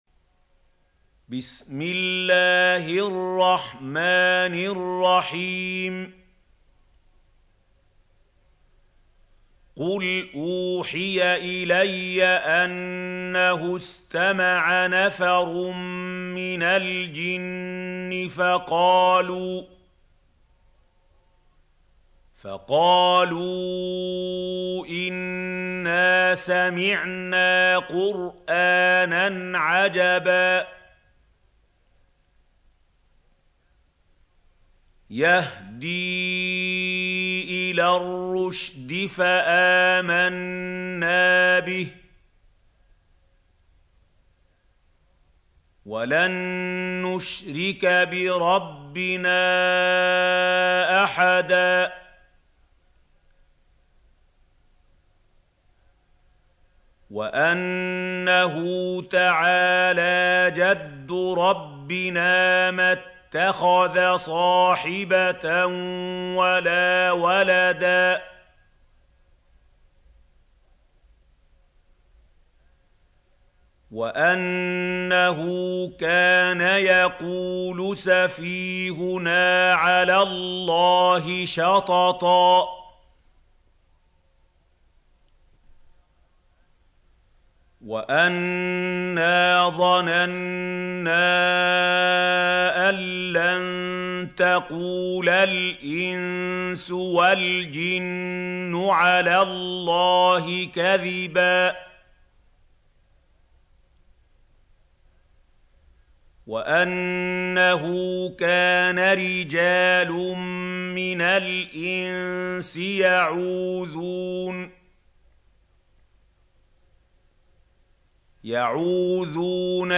سورة الجن | القارئ محمود خليل الحصري - المصحف المعلم